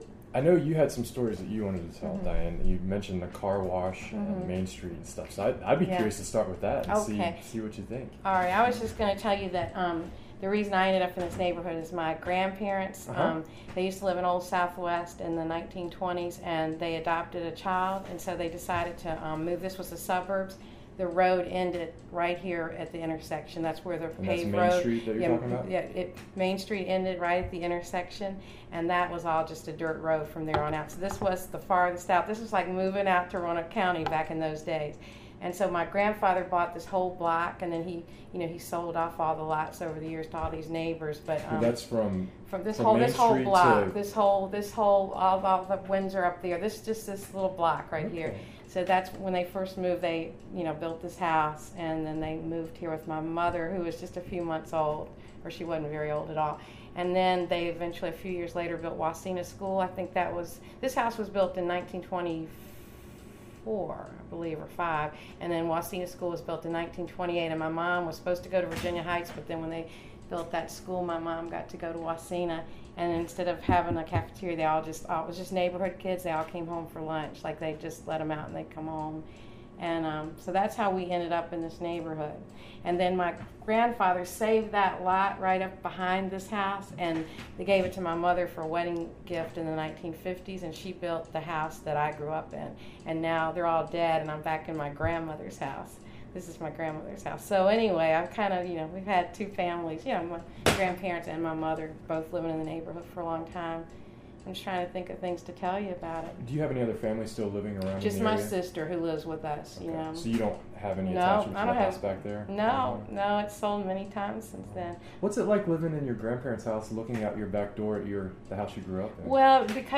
Neighborhood History Interview